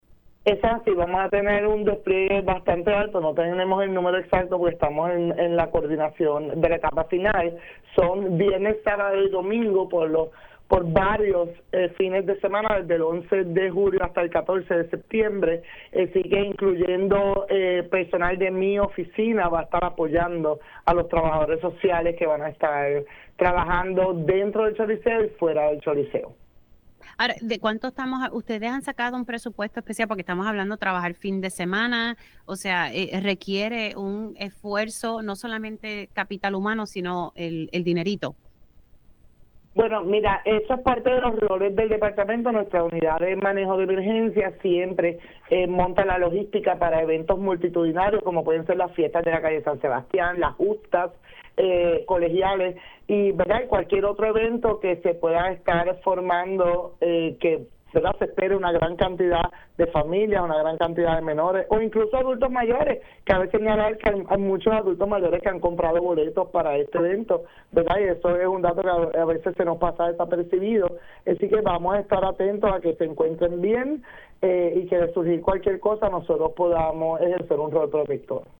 La información fue confirmada por la secretaria del Departamento de la Familia (DF), Suzanne Roig, en Pega’os en la Mañana, quien informó que desplegarán a su personal durante la residencia de conciertos del artista urbano.